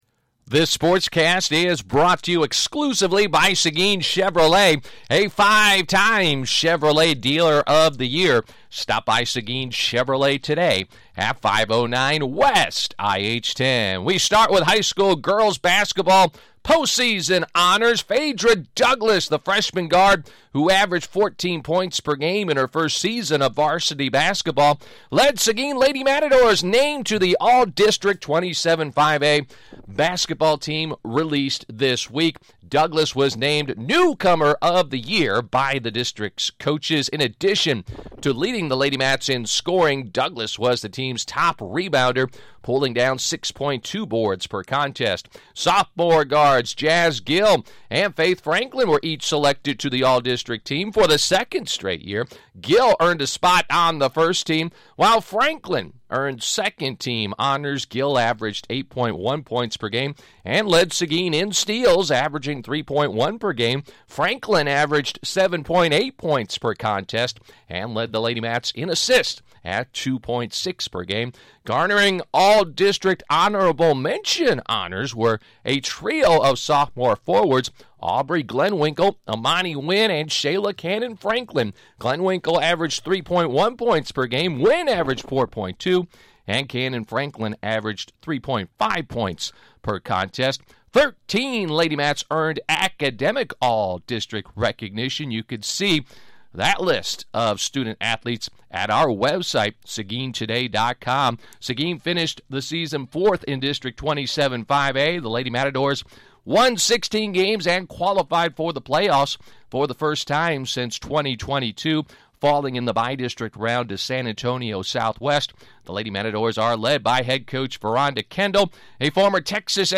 Thursday 2-19 Sportscast